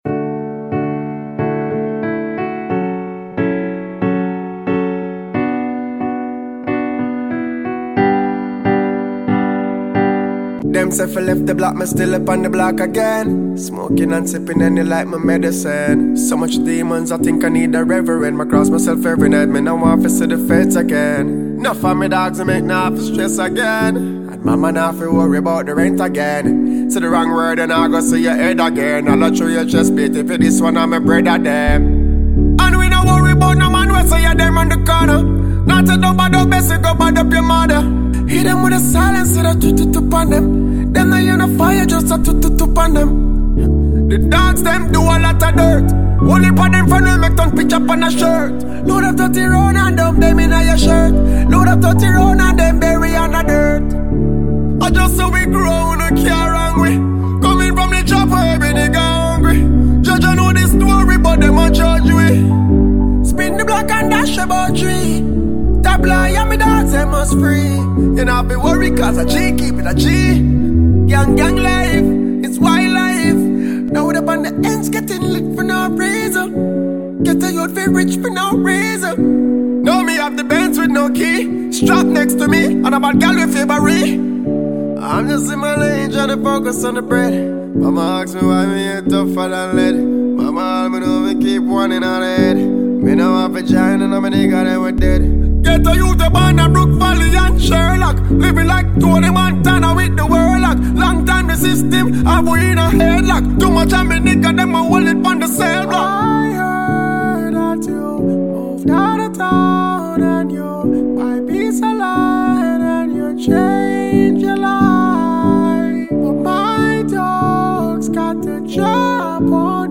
Dancehall Music